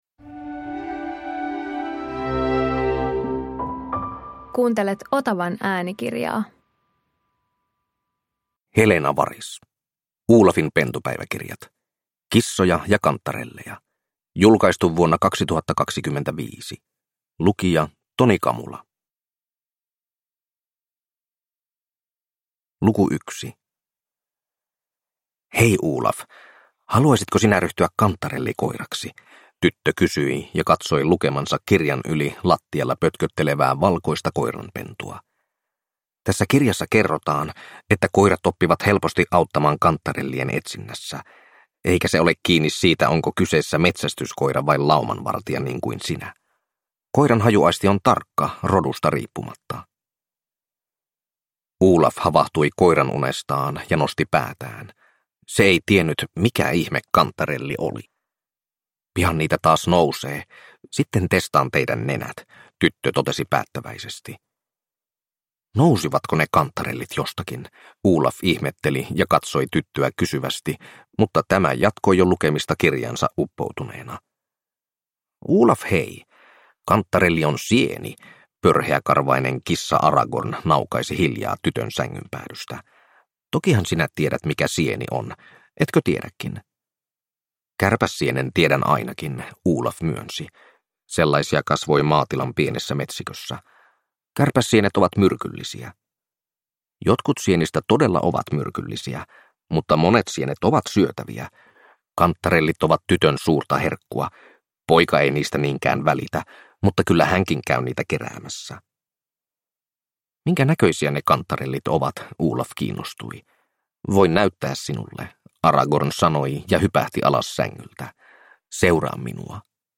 Olafin pentupäiväkirjat - Kissoja ja kantarelleja (ljudbok) av Helena Waris